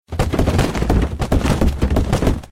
PadenieLesntica.ogg